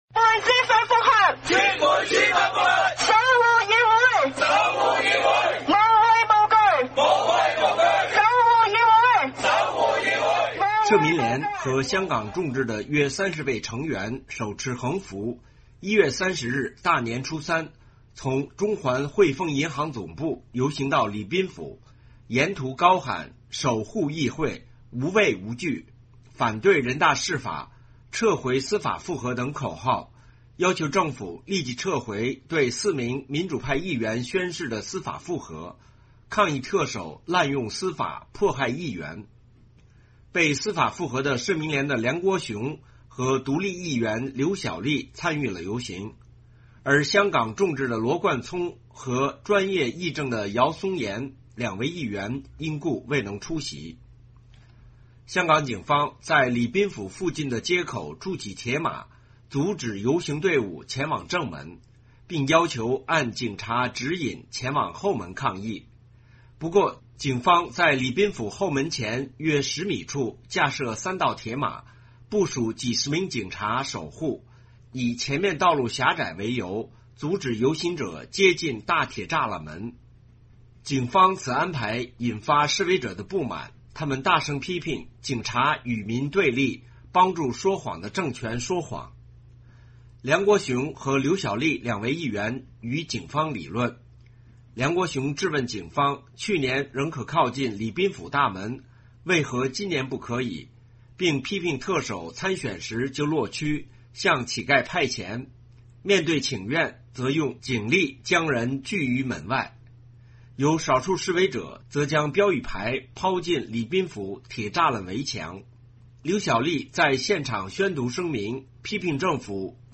社民连和香港众志的约30位成员手持横幅，1月30日大年初三，从中环汇丰银行总部游行到礼宾府，沿途高喊“守护议会、无畏无惧”、“反对人大释法”、“撒回司法覆核”等口号，要求政府立即撤回对4名民主派议员宣誓的司法覆核，抗议特首“滥用司法，迫害议员”。
警方此安排引发示威者不满，他们大声批评警察与民对立，帮助说谎的政权说谎。